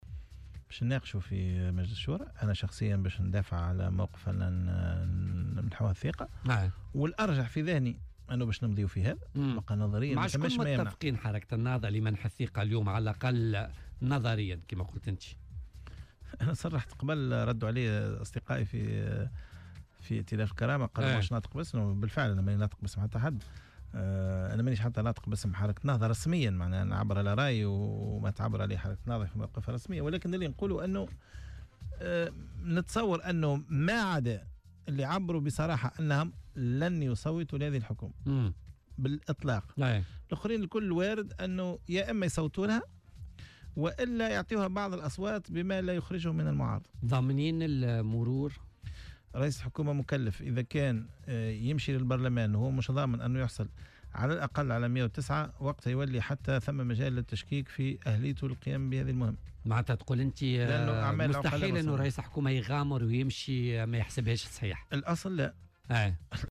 وأضاف في مداخلة له اليوم في برنتمج "بوليتيكا" على "الجوهرة أف أم" أنه سيدفع شخصيا في اتجاه منح الثقة للحكومة الجديدة، مشيرا إلى أنه على الأرجح أن تمضي الحركة في هذا الاتجاه، وفق تعبيره.